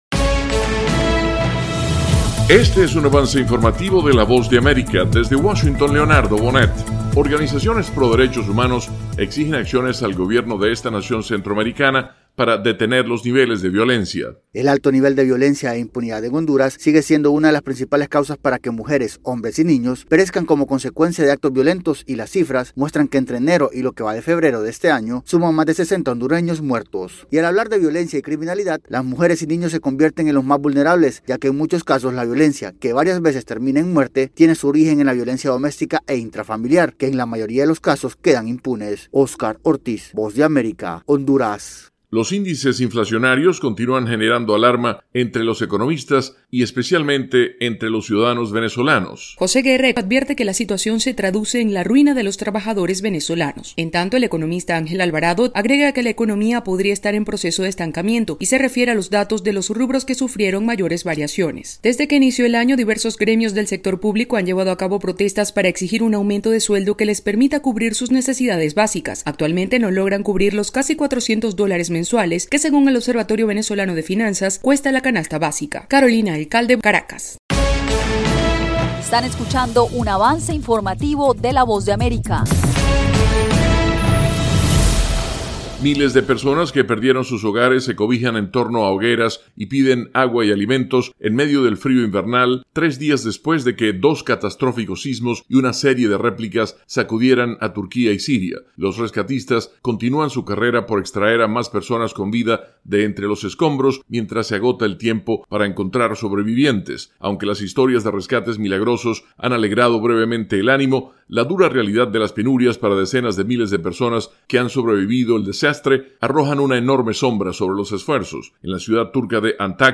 El siguiente es un avance informativo presentado por la Voz de América, desde Washington